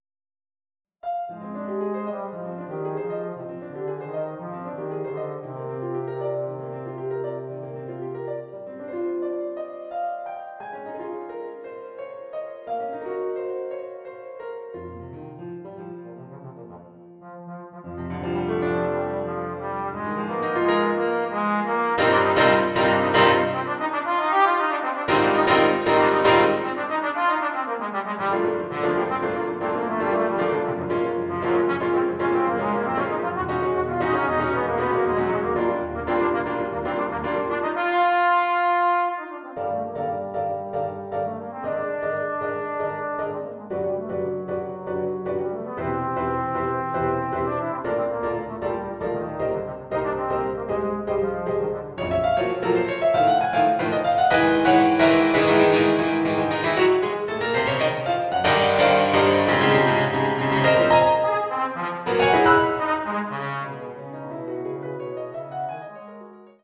The trombone solo part is identical for all versions.